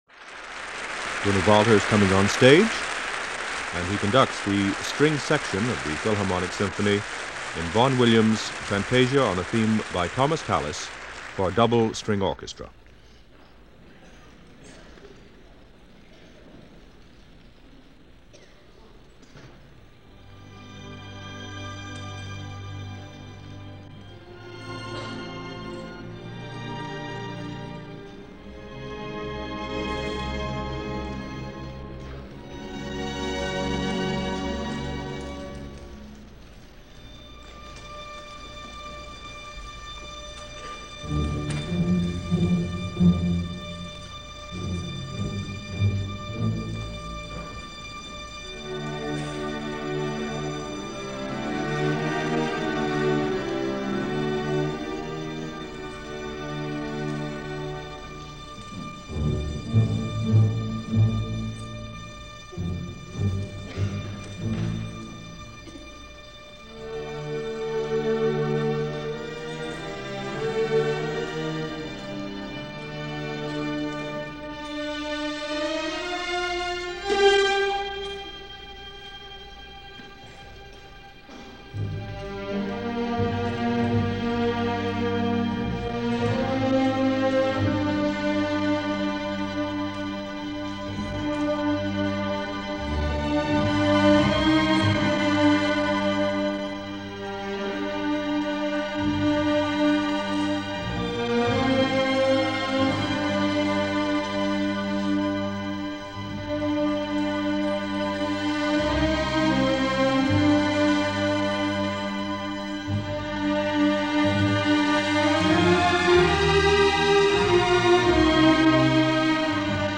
Very familiar territory this weekend, played by one of the world’s great orchestras, led by one of the giants of the Podium of the 20th Century.
One work – Vaughan-Williams incandescent Fantasia on A Theme By Thomas Tallis, with the New York Philharmonic conducted by Bruno Walter, from a concert broadcast approximately February 15, 1953.